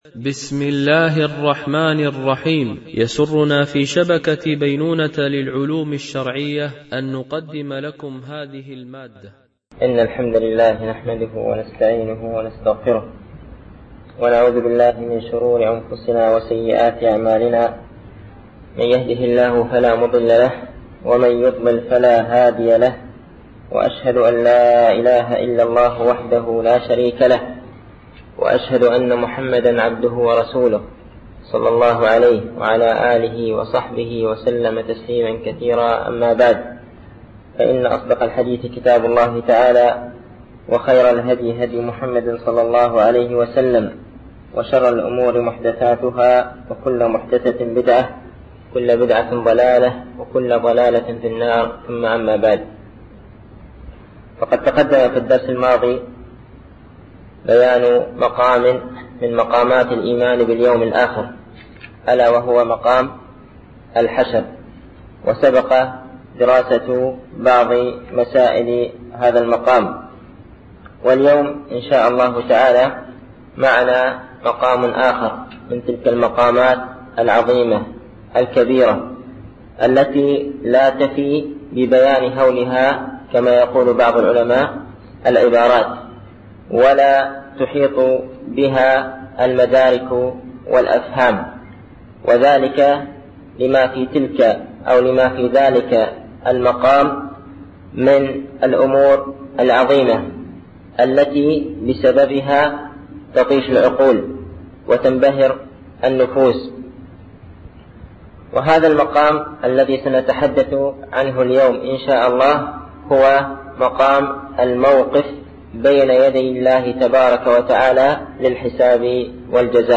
) الألبوم: شبكة بينونة للعلوم الشرعية التتبع: 43 المدة: 40:04 دقائق (9.21 م.بايت) التنسيق: MP3 Mono 22kHz 32Kbps (CBR)